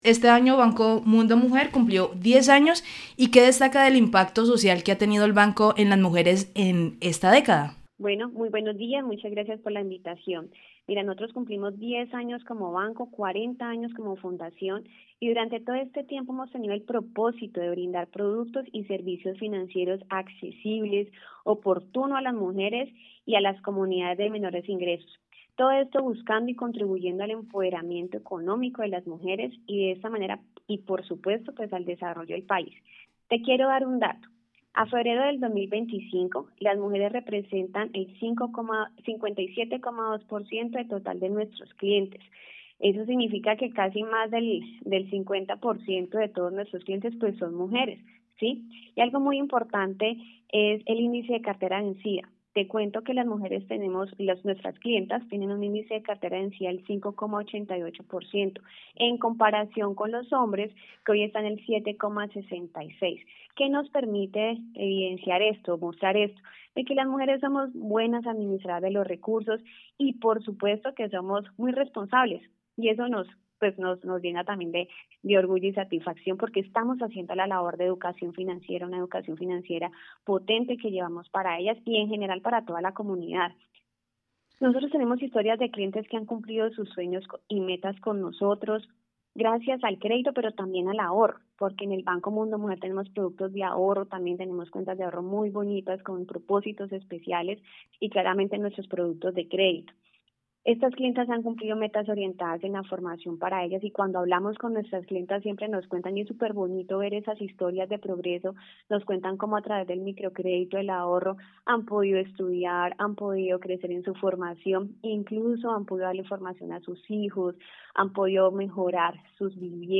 ENTREVISTA MUNDO MUJER